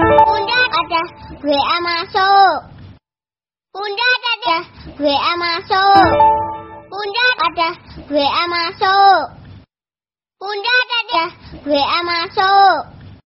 Dibuat Sendiri Nada Dering WA Sebut Nama
Kategori: Nada dering